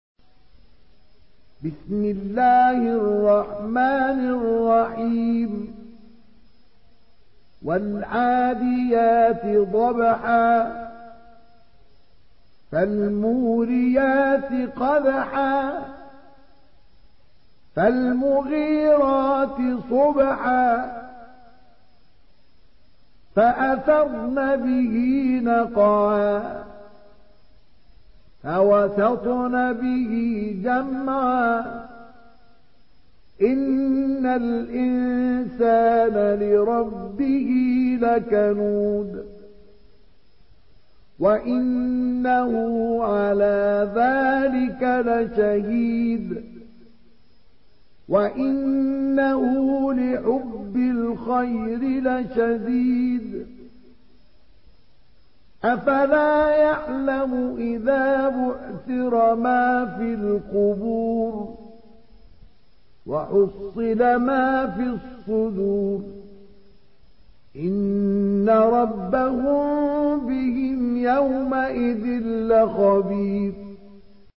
Surah العاديات MP3 in the Voice of مصطفى إسماعيل in حفص Narration
Surah العاديات MP3 by مصطفى إسماعيل in حفص عن عاصم narration.
مرتل